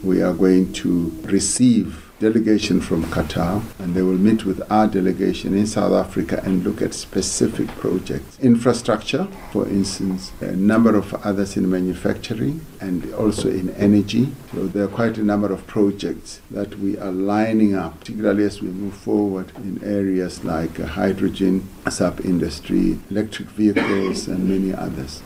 Die president het bygevoeg Katar het die ervaring, tegnologie en kapitaal in die petrochemiese, aardgas- en energie-infrastruktuur:
ENG-RamaphosaOnQatarPartnership.mp3